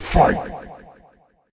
snd_boxing_fight.ogg